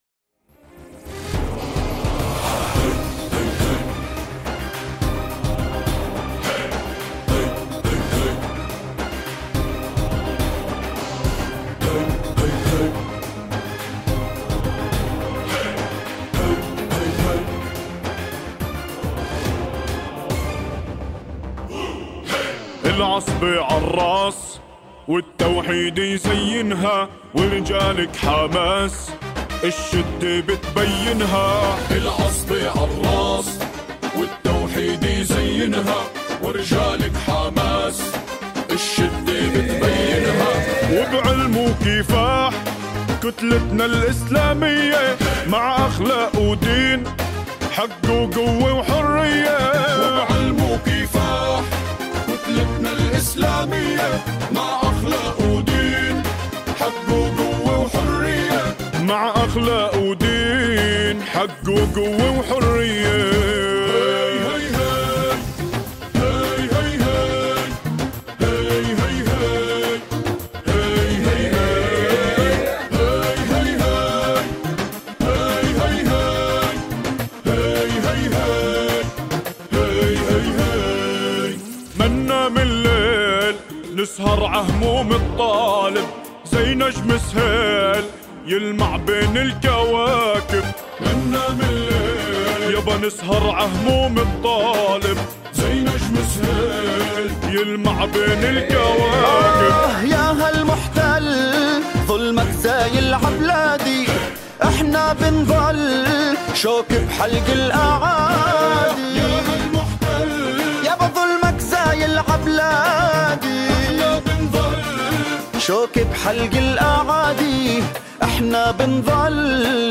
أناشيد فلسطينية... علم وكفاح